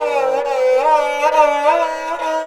SARANGHI3 -L.wav